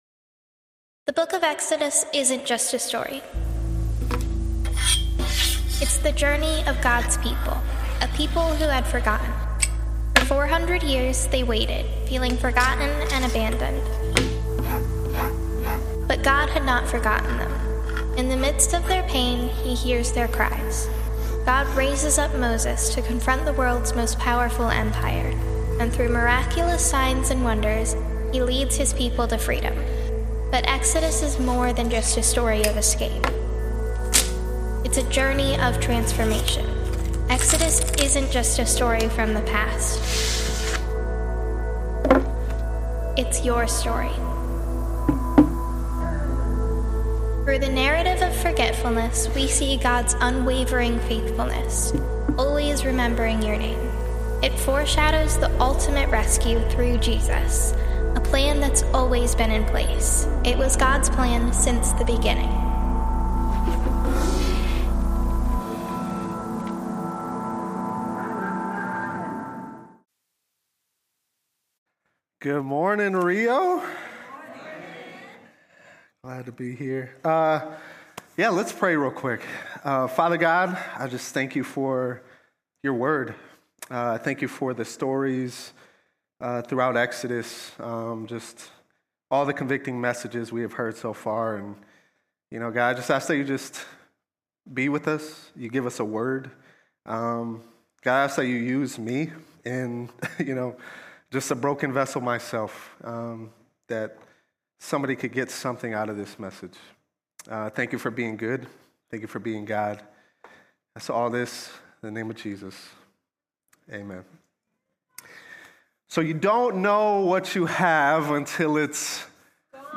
The sermon draws parallels to modern-day misplaced trust and the danger of letting faith become ordinary.